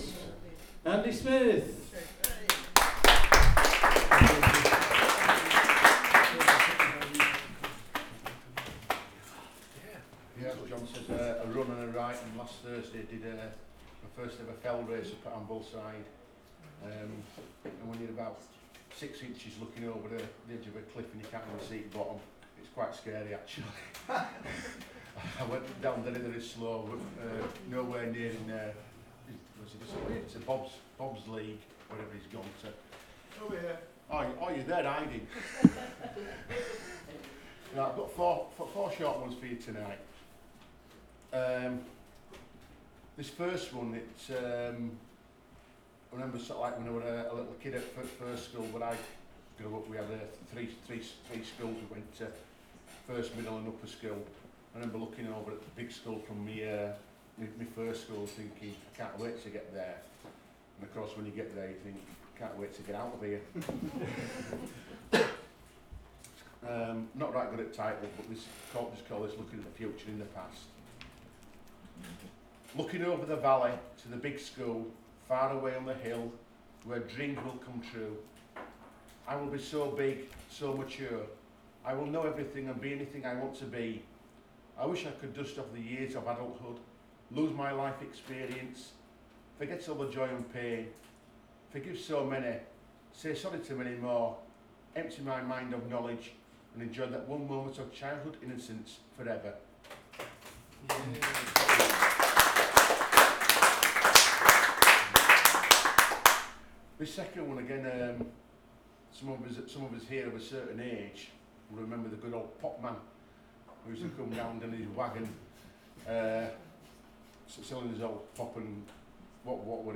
poem, poetry, Poetry reading, Uncategorized, Voice Recording
This is an audio recording of me reading some of my own poetry at the Blind Pig, Sowerby Bridge, August 2016.